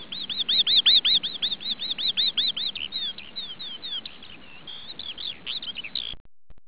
skylark.aiff